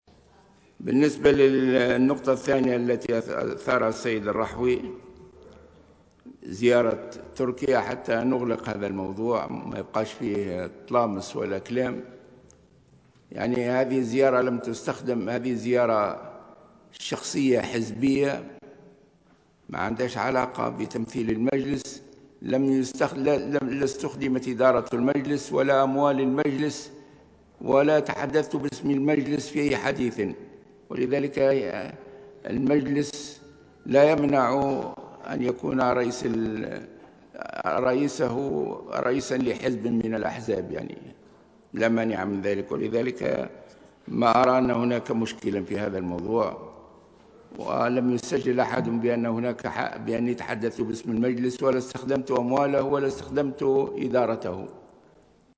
و جاء توضيح الغنوشي، ردّا عن سؤال النائب منجي الرحوي، الذي طالب بتغيير جدول أعمال الجلسة العامة و تخصيص جزء منها، لمساءلته حول زيارته الأخيرة إلى تركيا و الاجتماع المغلق الذي جمعه بالرئيس التركي رجب طيّب أردوغان.